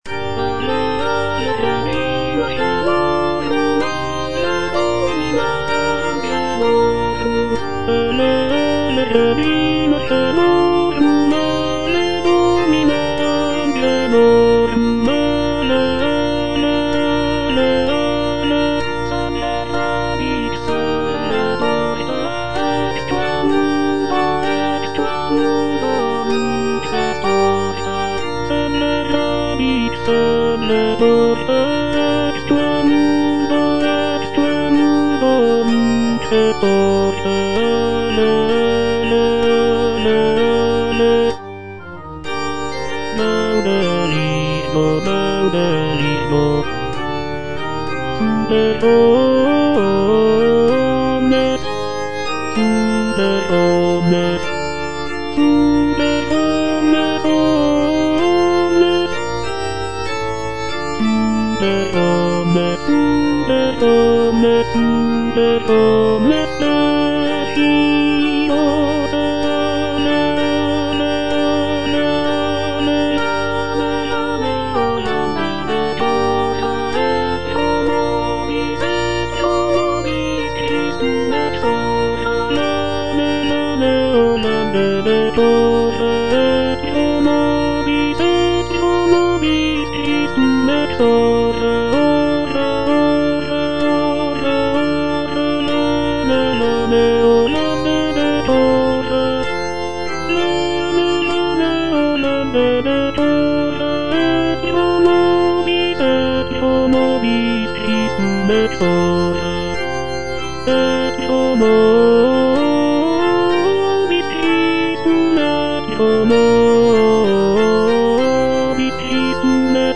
I. LEONARDA - AVE REGINA CAELORUM Tenor (Voice with metronome) Ads stop:
"Ave Regina caelorum" is a sacred vocal work composed by Isabella Leonarda, a 17th-century Italian composer and nun. The piece is a hymn dedicated to the Virgin Mary, often sung during the season of Lent.